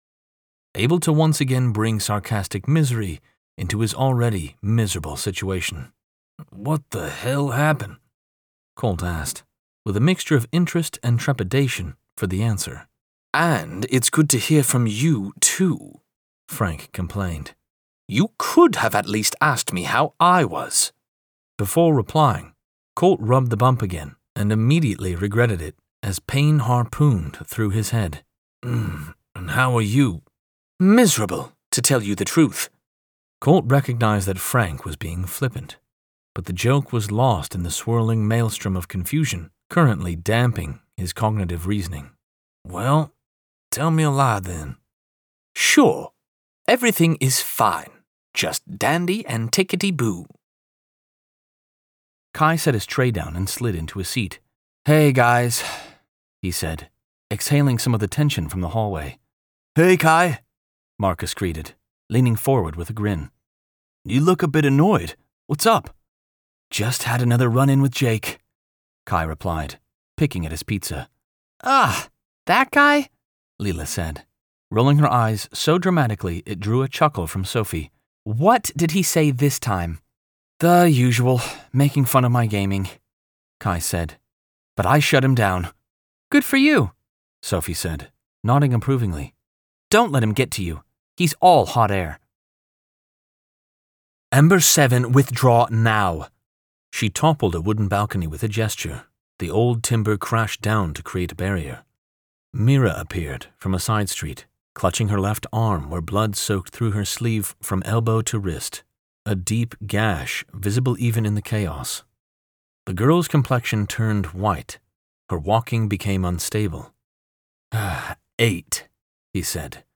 Audiobook Narrator & Voiceover
I strive to create realistic characters and bring a full cast of voices ready for the best listening experience!
Audition Highlight Reel, SciFi/Fantasy